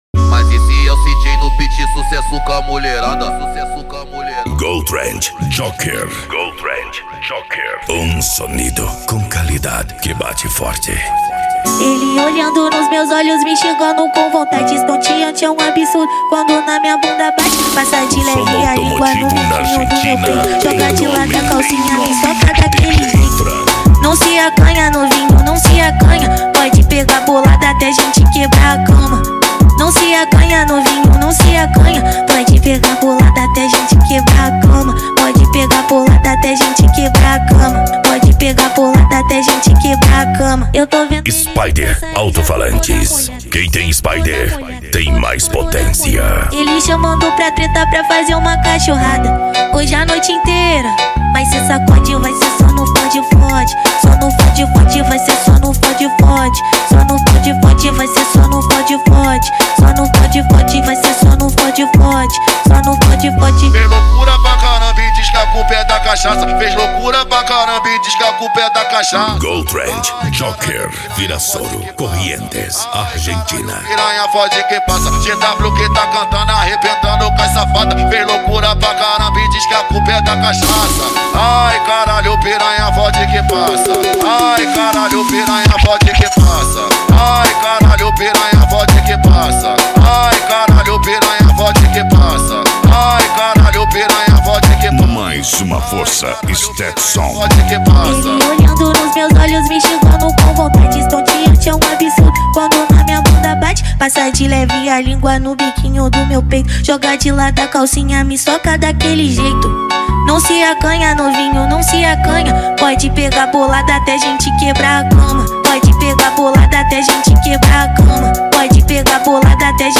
Arrocha
Funk
Remix